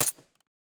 sounds / weapons / _bolt / dmr_2.ogg